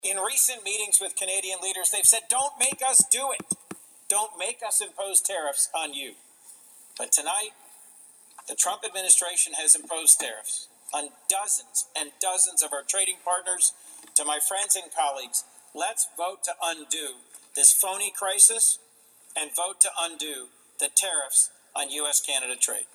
U.S. Senator Chris Coons spoke on the U.S. Senate floor, sharply criticizing President Trump’s newly imposed global tariffs. Senator Coons last night called on his colleagues on both sides of the aisle to support a resolution that would end the tariffs President Trump has imposed on Canada…